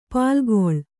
♪ pālgoḷ